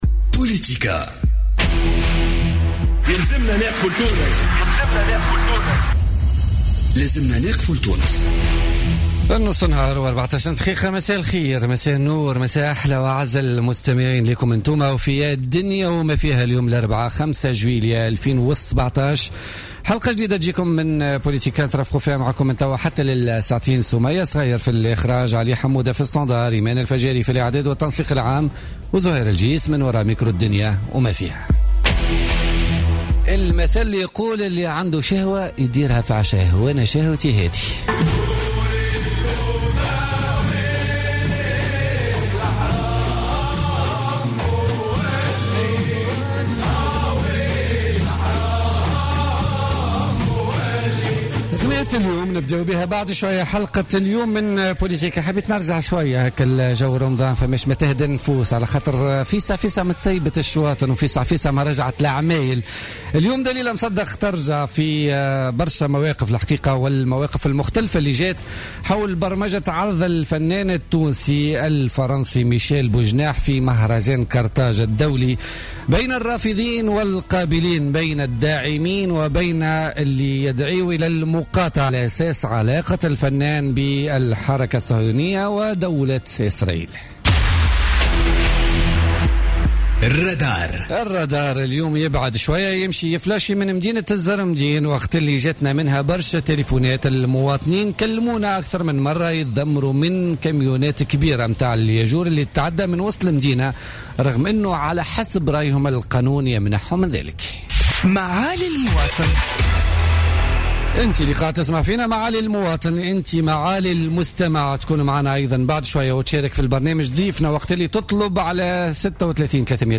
ليلى الشتاوي، ضيفة بوليتيكا